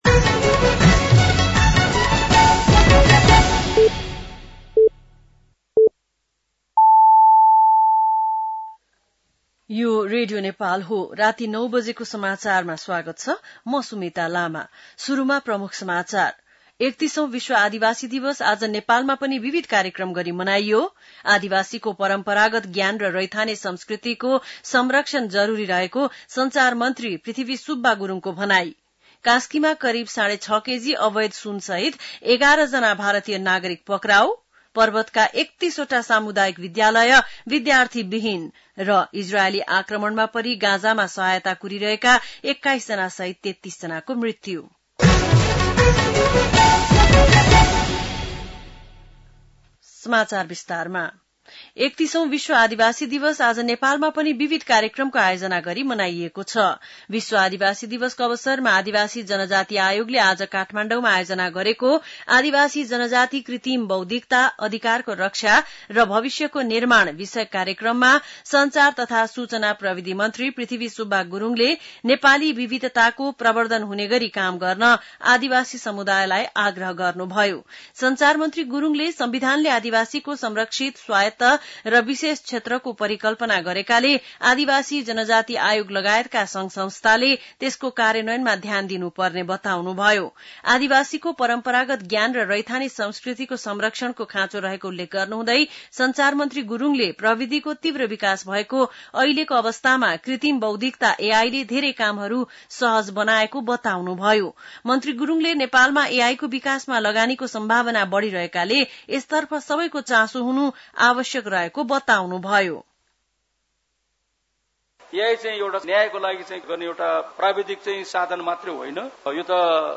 बेलुकी ९ बजेको नेपाली समाचार : २४ साउन , २०८२